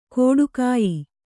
♪ kōḍukāyi